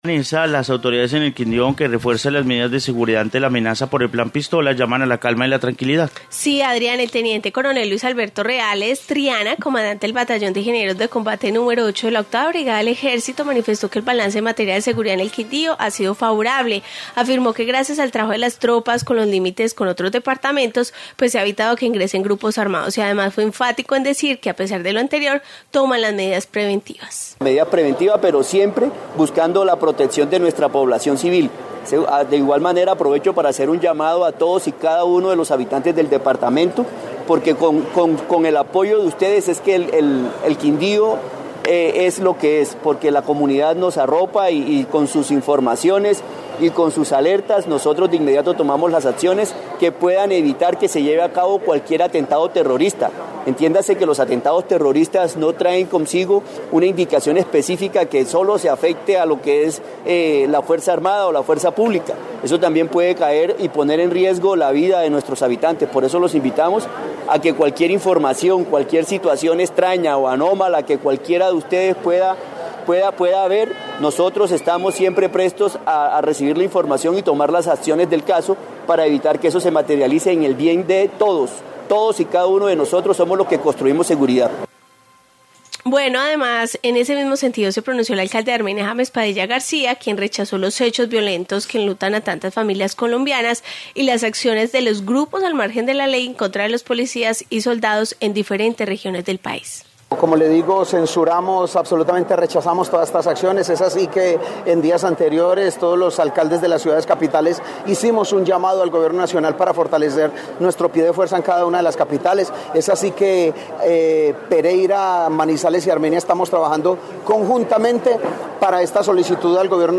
Informe plan pistola